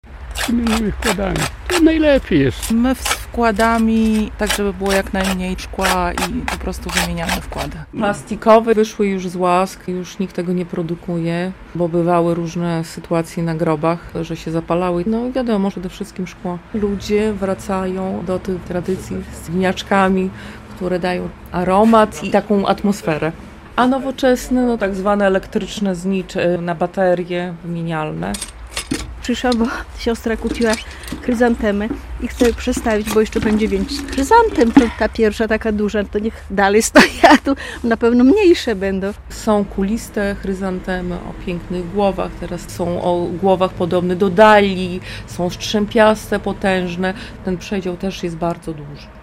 Jak ozdabiamy groby bliskich zmarłych? - relacja